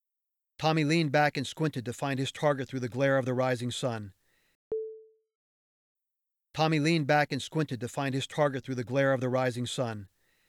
Here’s my raw sample.
I’m using Audacity 2.1.0 to record an audiobook (fiction).
I’m using a ATR2100-USB Audio-Technica mic plugged into my Asus/Windows 10 laptop and recording in a walk-in closet in the central part of my house, using a cardboard box lined with noise-protecting foam to encase the mic. I have the foam sock over the mic to shield breaths, etc.